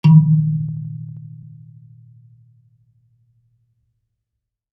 kalimba_bass-D#2-ff.wav